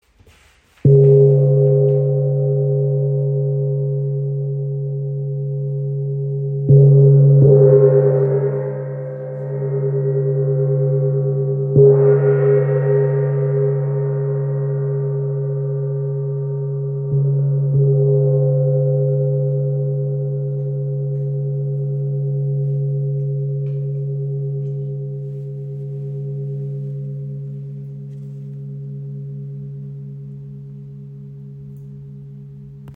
Tam Tam Gong | Chao Gong | Ø 50 cm im Raven-Spirit WebShop • Raven Spirit
Tam Tams/Chau Gongs zeichnen sich durch einen rundum nach hinten geschmiedeten Rand aus, welcher den Klangcharakter mitbestimmt. Der Sound ist voluminös und erinnert im Aufbau an übereinander liegende Klangebenen. So kann ein Soundgebilde aus Bass und Obertönen entstehen. Solche Gongs mit Rand erzeugen einen sehr meditativen Gesamtklang.